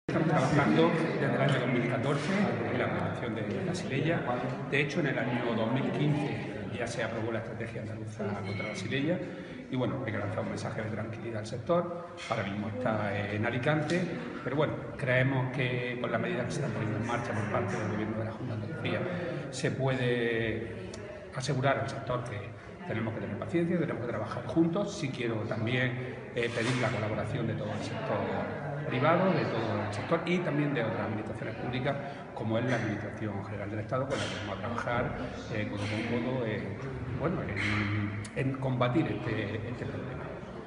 Rodrigo Sánchez Haro, en la Conferencia Sectorial de Agricultura.
Declaraciones consejero Xylella